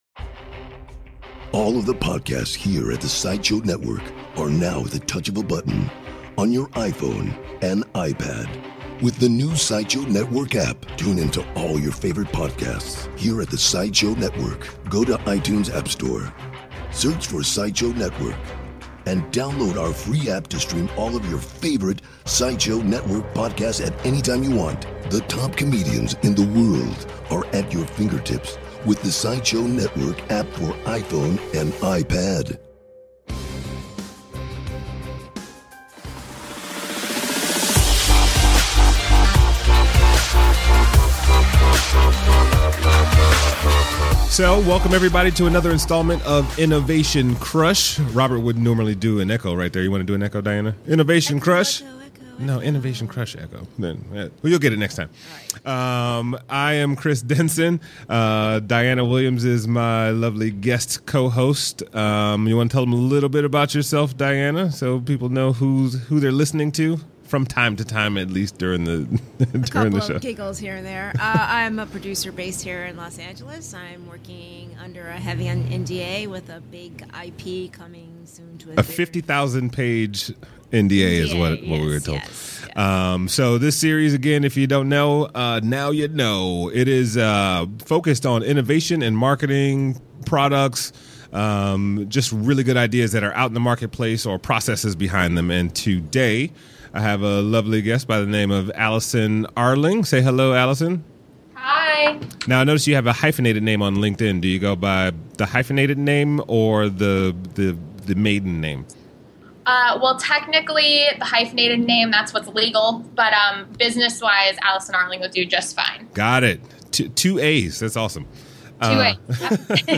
Special Guest Host